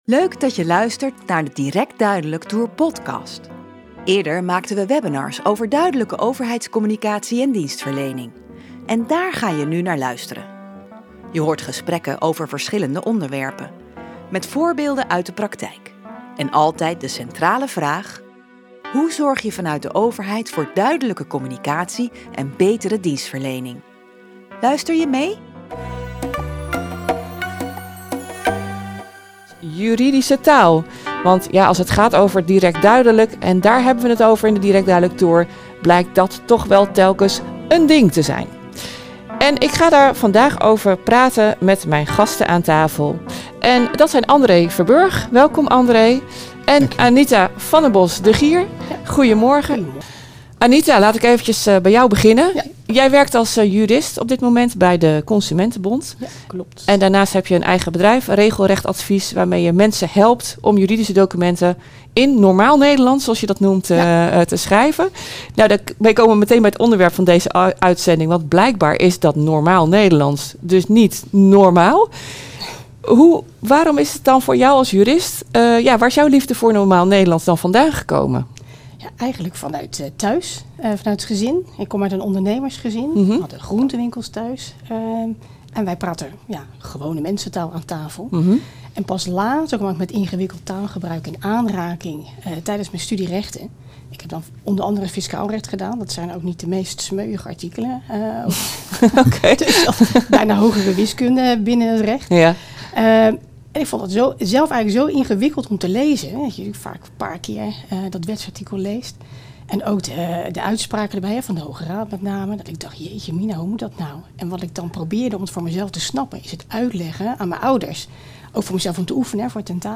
Luister het webinar van de Direct Duidelijk Tour terug in deze podcast.
Je hoort gesprekken over verschillende onderwerpen.